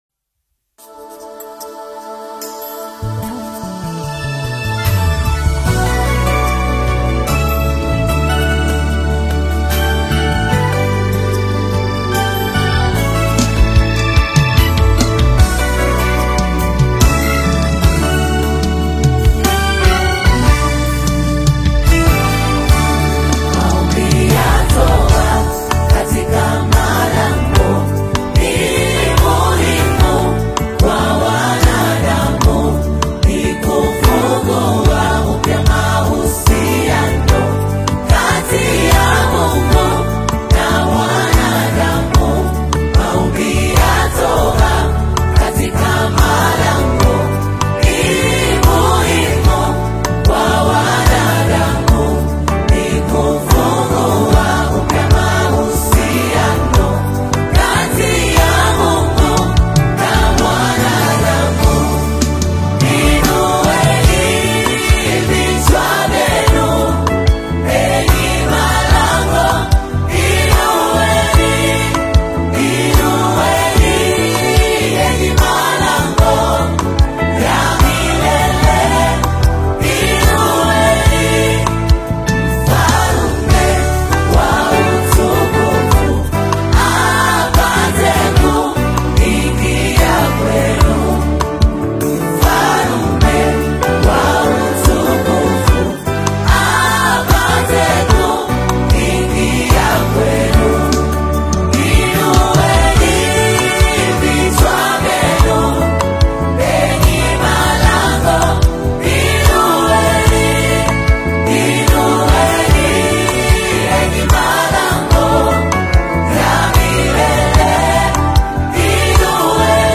is a heartfelt Tanzanian gospel live performance